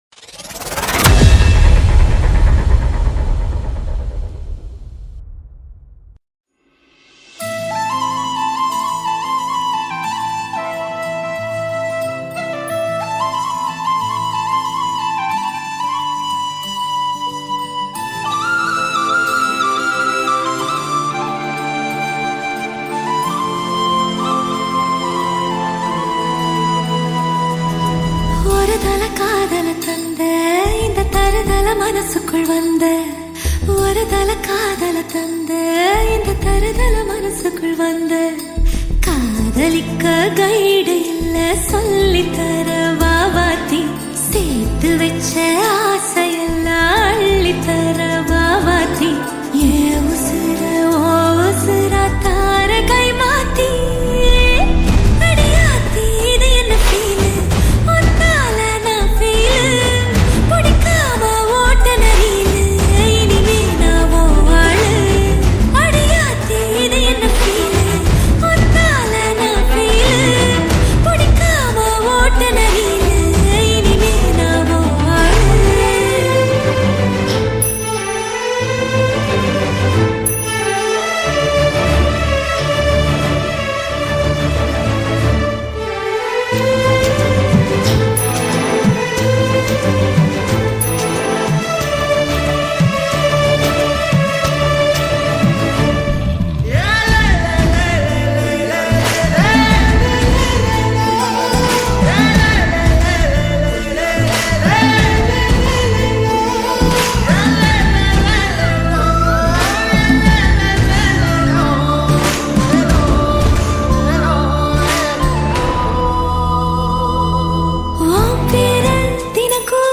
Tamil 8D Songs